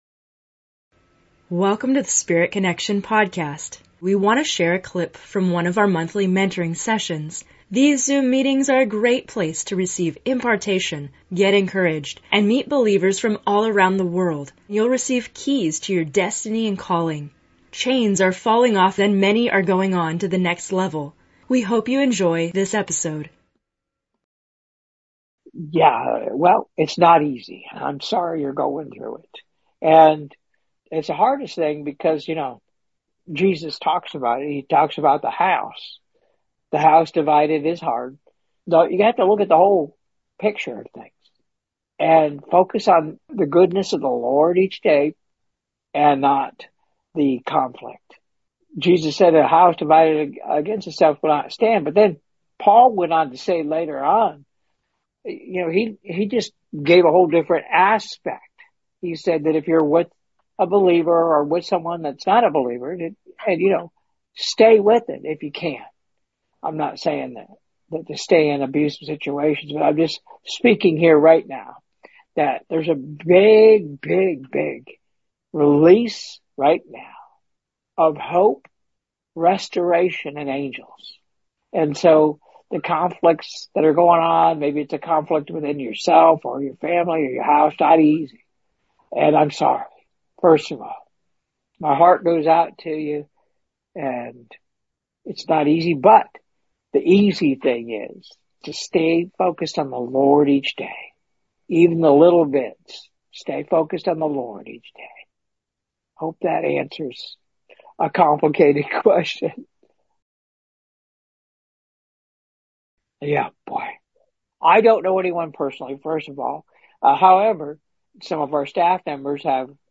In this episode of Spirit Connection, we have a special excerpt from a Q&A session in a recent Monthly Mentoring Session.